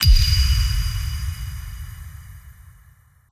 DSGNStngr_Kill Confirm Metallic_02.wav